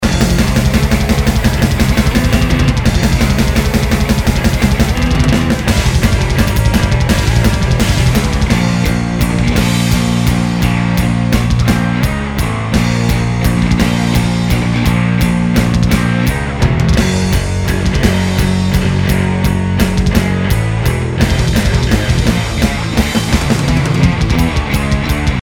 I just recorded a song using two channels for bass, one that goes directly into a DI and the other that goes thru my guitar amp, miked with a SM57.
Too much growl?